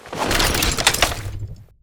wav / general / combat / weapons / mgun / holster.wav
holster.wav